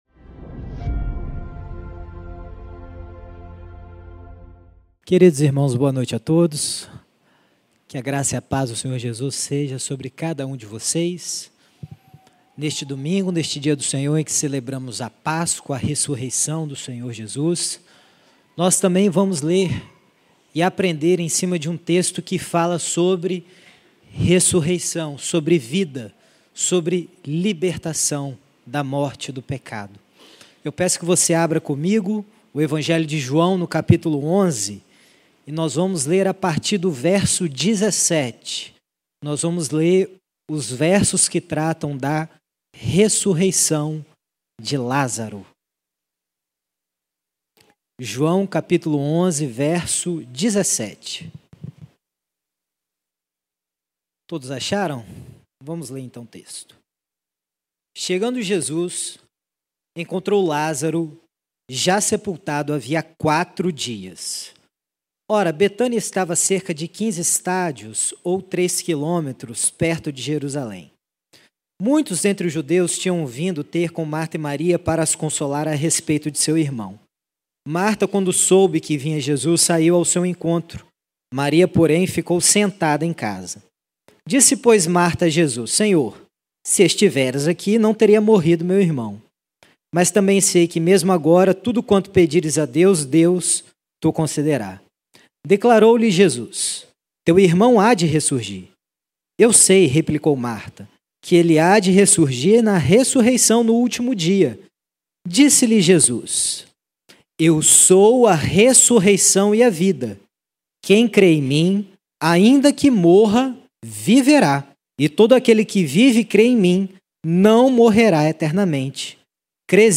Neste sermão poderoso